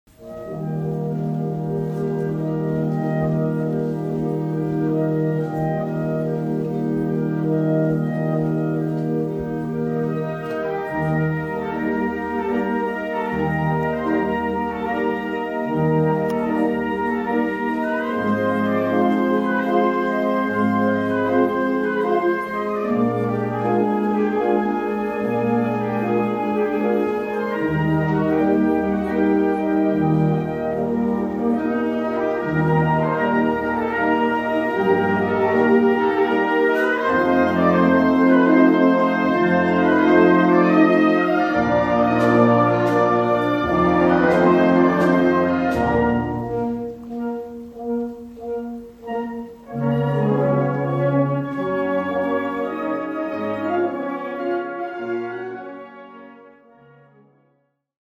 Gattung: Polka Mazur
Besetzung: Blasorchester